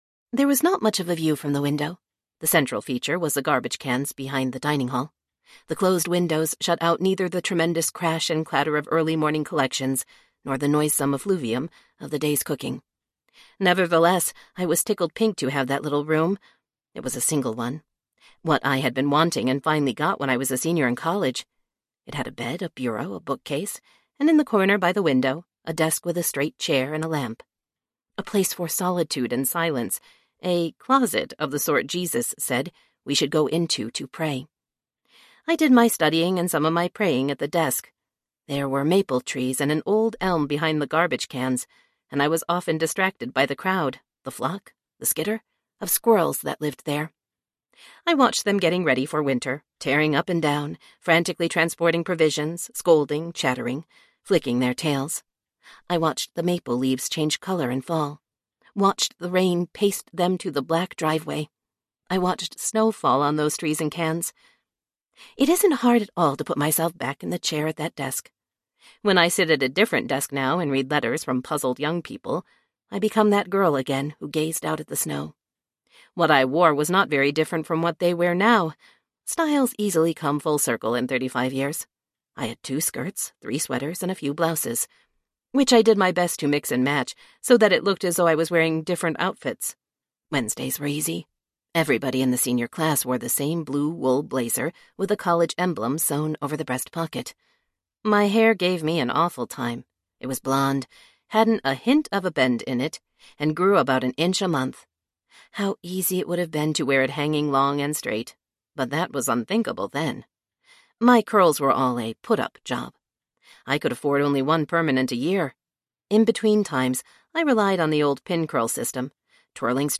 Passion and Purity Audiobook
4.6 Hrs. – Unabridged